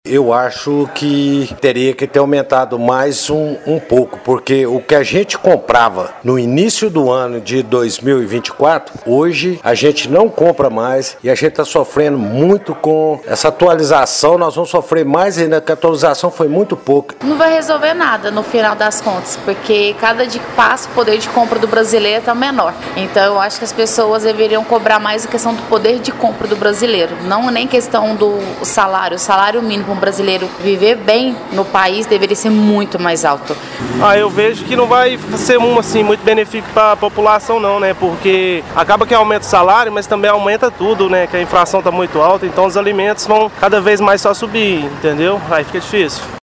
O Jornal da manhã foi as ruas para saber da população se o aumento foi significativo o bolso do trabalhador ou não. As respostas foram unânimes: o acréscimo no valor continua sem melhorias reais no poder de compra da população: